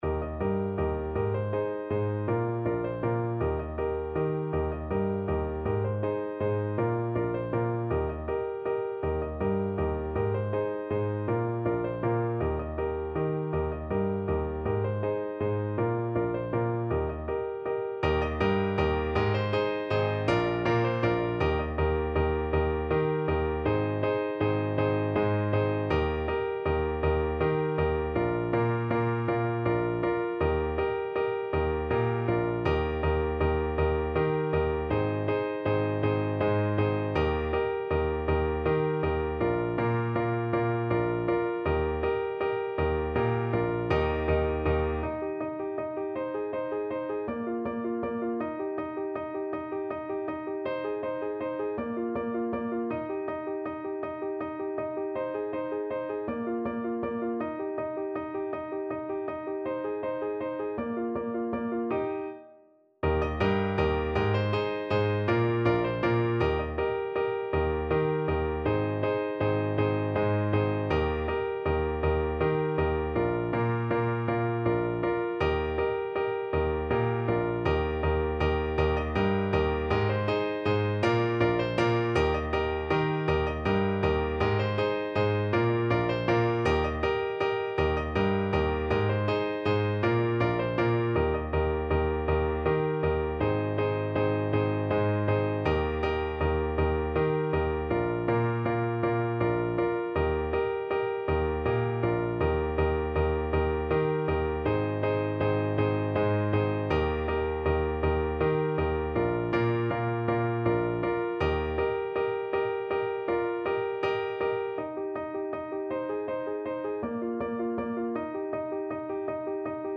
Play (or use space bar on your keyboard) Pause Music Playalong - Piano Accompaniment Playalong Band Accompaniment not yet available reset tempo print settings full screen
3/4 (View more 3/4 Music)
Eb major (Sounding Pitch) (View more Eb major Music for Tuba )
Allegro Vivo = 160 (View more music marked Allegro)
Classical (View more Classical Tuba Music)